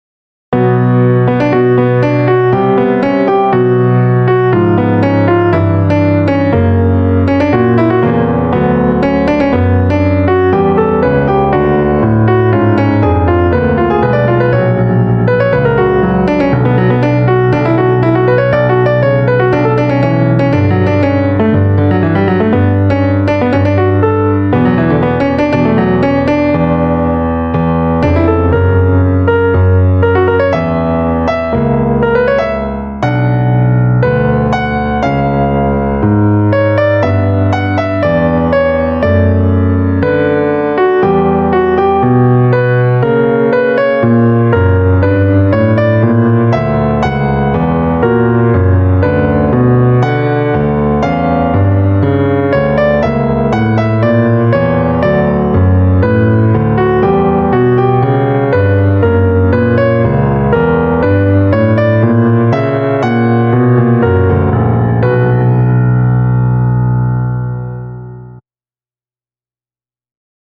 ３声のアレンジ。
普通に弾くのを想定したアレンジ。